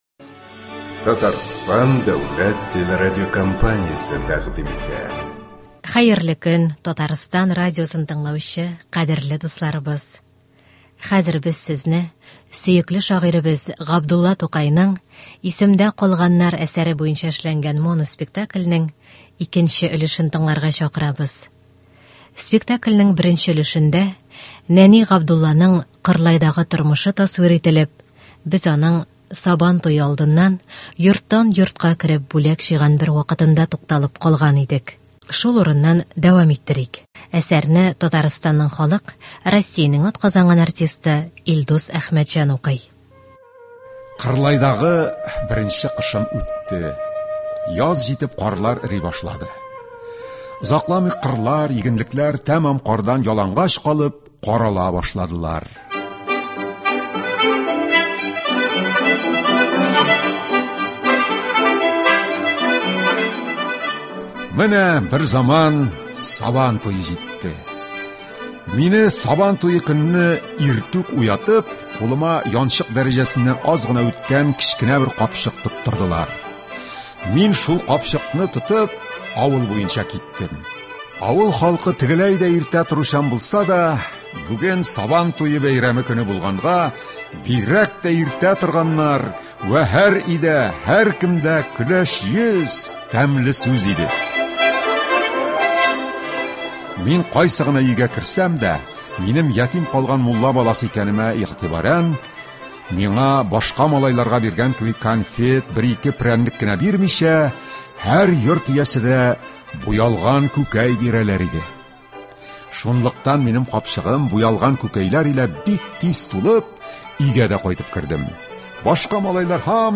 “Исемдә калганнар” Радиоспектакль премьерасы.